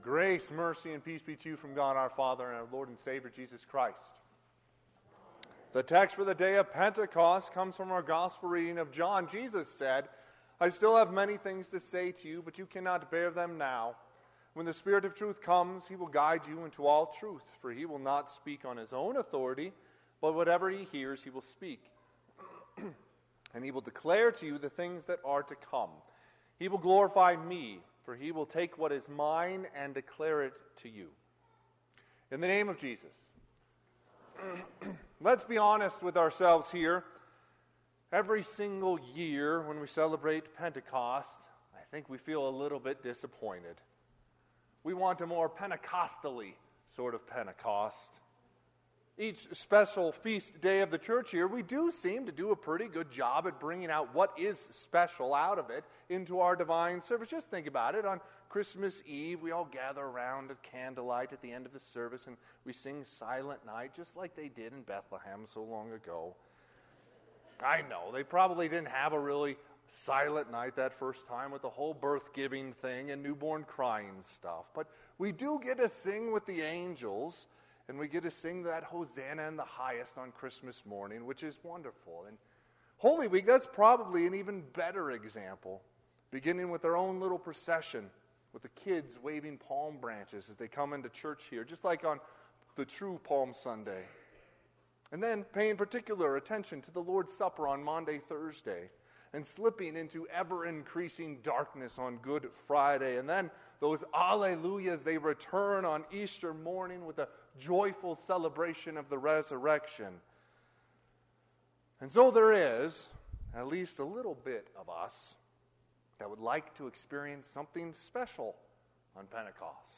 Sermon - 5/19/2024 - Wheat Ridge Evangelical Lutheran Church, Wheat Ridge, Colorado